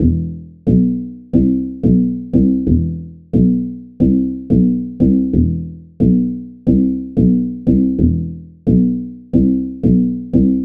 Bass Loop C Minor 90 Bpm
描述：This is a nice funky but mellow bass loop in C minor at 90 bpm but you can stretch it easily if you like
标签： 90 bpm Funk Loops Bass Synth Loops 1.79 MB wav Key : C
声道立体声